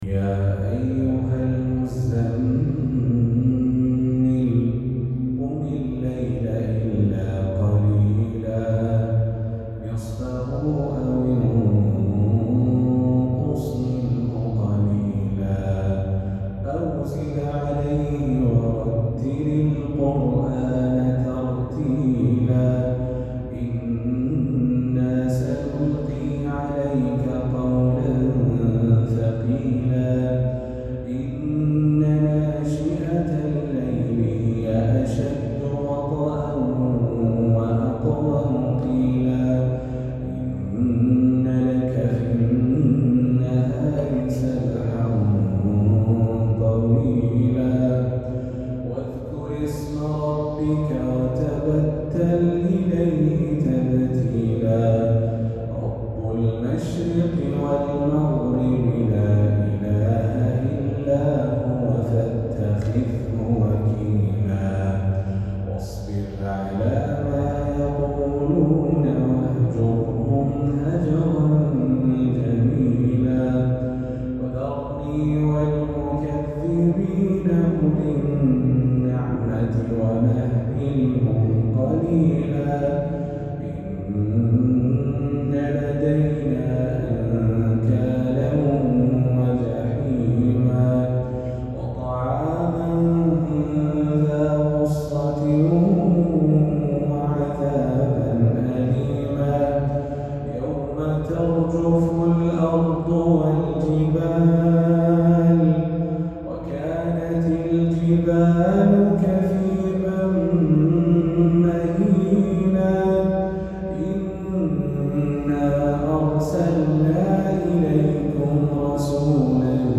فجرية خاشعة